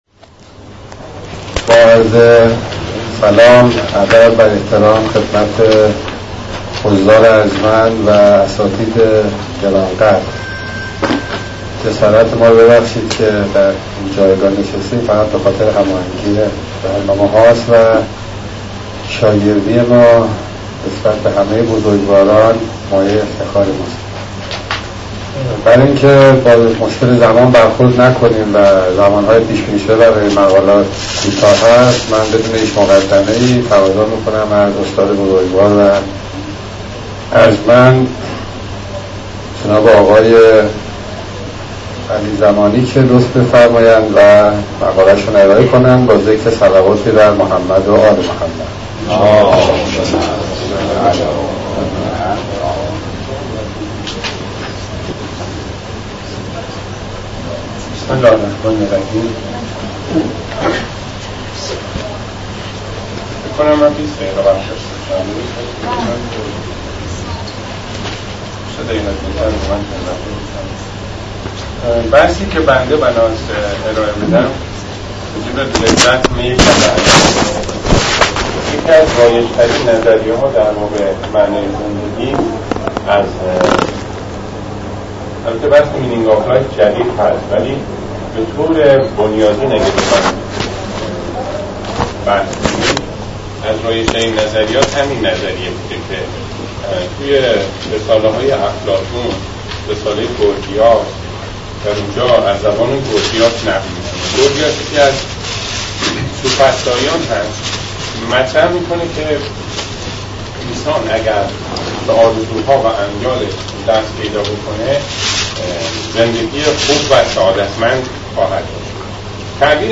سخنراني
در همايش اسلام و ارزش‌هاي متعالي - تهران ارديبهشت 95